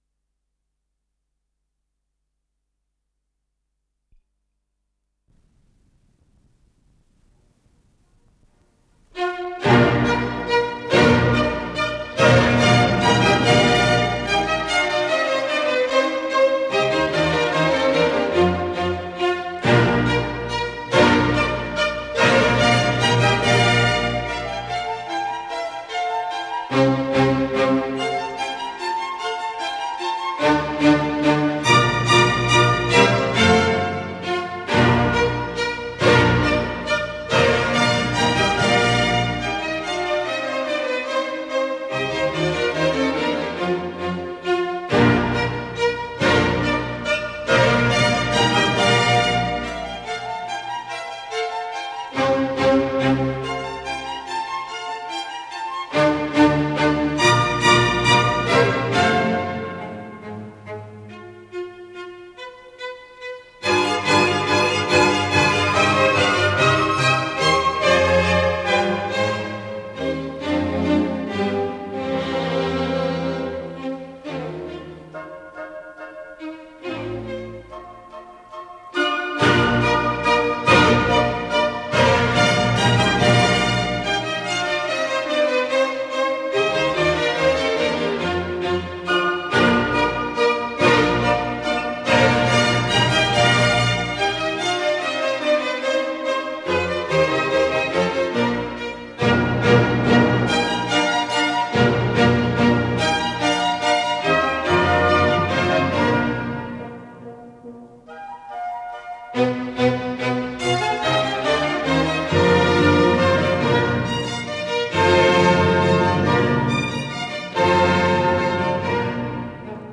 с профессиональной магнитной ленты
ИсполнителиМосковский камерный оркестр
Художественный руководитель и дирижёр - Рудольф Баршай
ВариантДубль моно